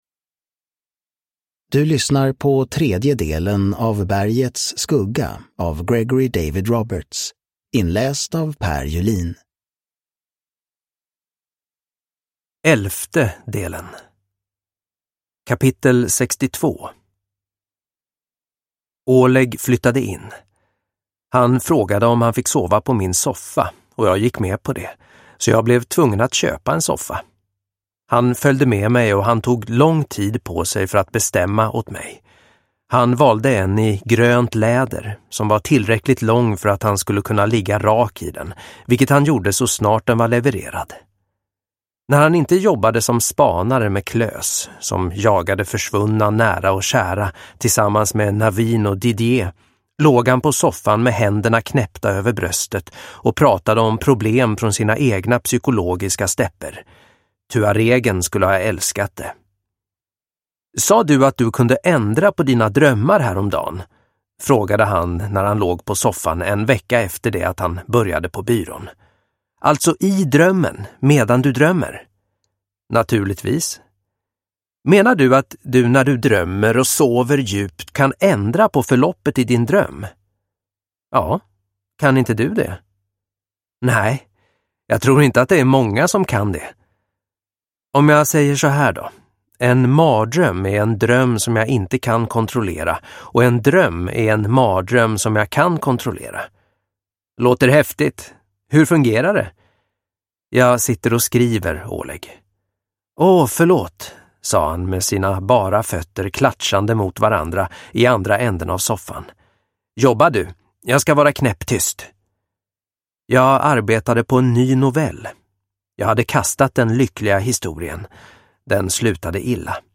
Bergets skugga. Del 3 – Ljudbok – Laddas ner